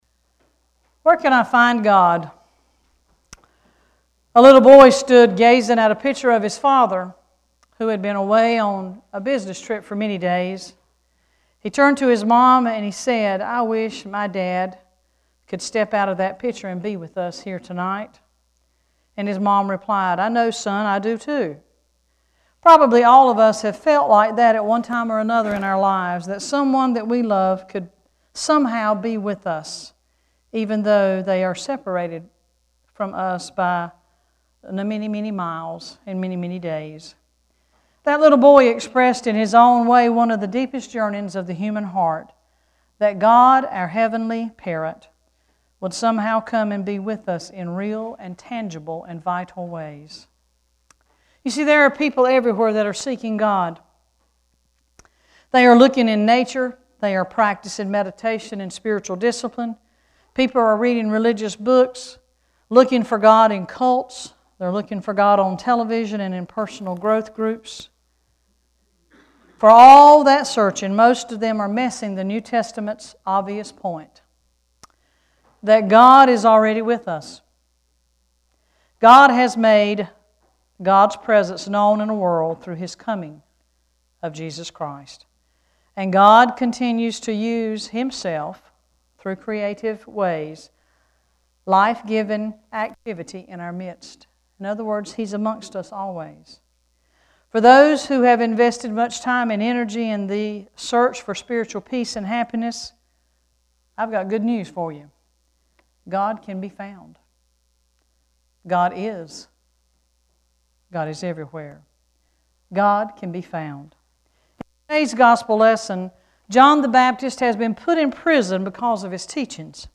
This weeks scripture and sermon